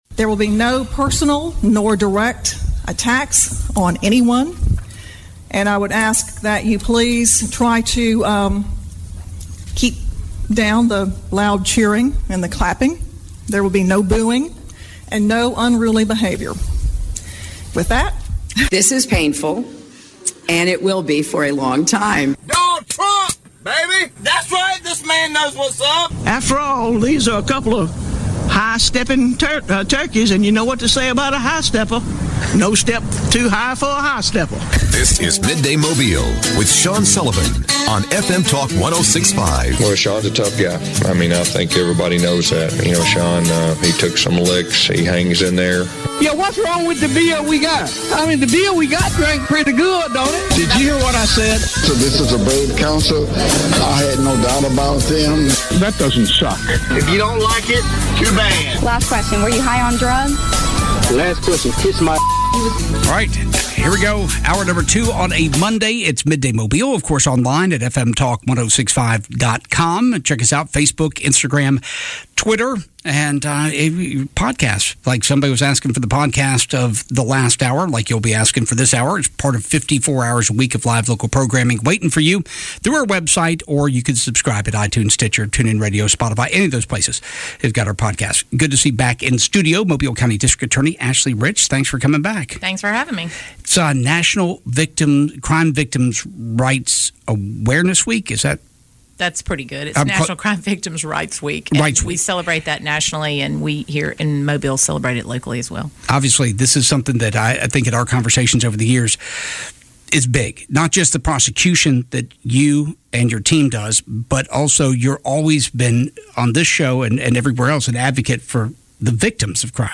Listen to their conversation through our podcast here